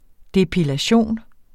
Udtale [ depilaˈɕoˀn ]